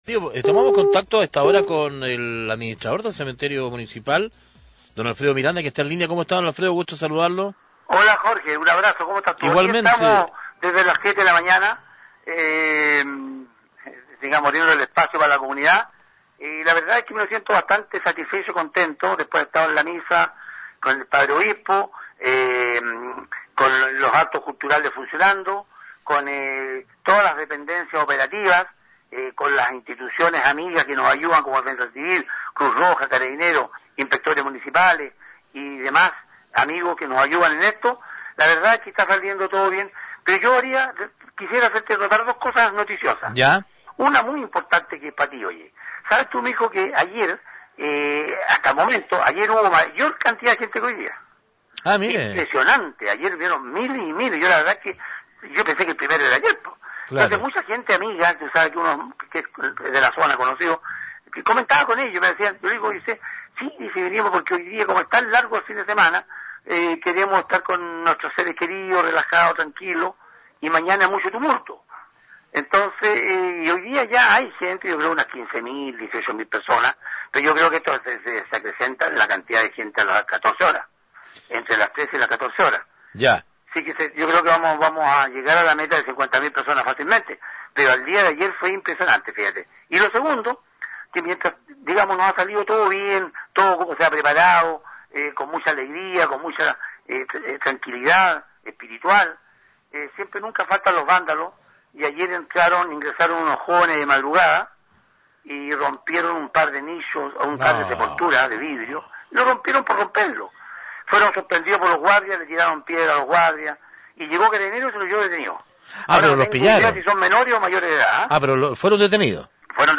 Entrevistas de Pingüino Radio